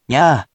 We have our computer friend, QUIZBO™, here to read each of the hiragana aloud to you.
In romaji, 「みゃ」 is transliterated as「mya」which sounds like「myahh」.